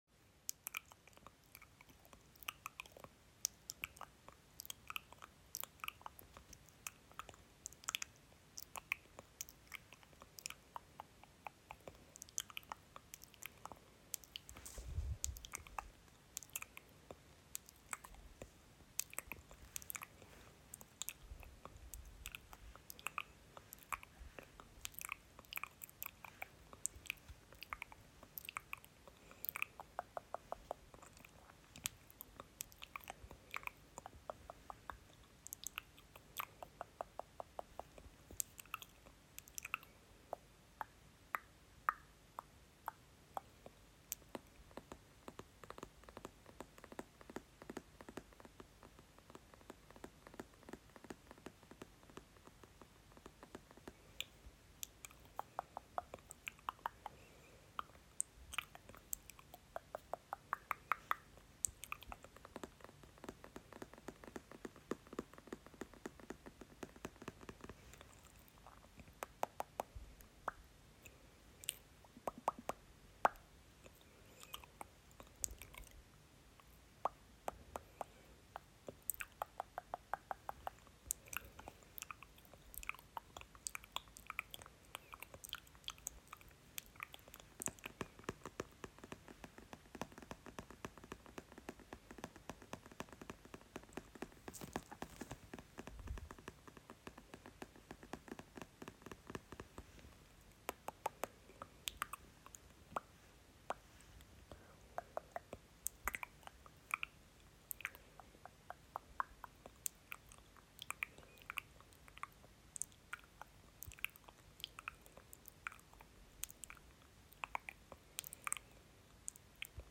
Mouth sounds and tapping sounds